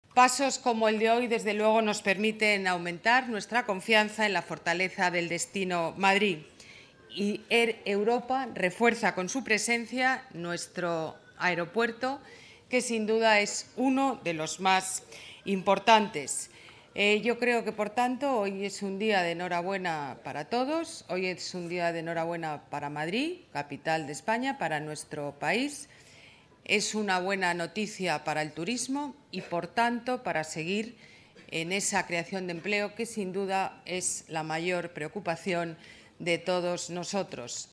La alcaldesa asiste a la inauguración del hub que estrenan hoy Air Europa y Sky Team en el Adolfo Suárez Madrid-Barajas
Nueva ventana:Declaraciones de la alcaldesa de Madrid, Ana Botella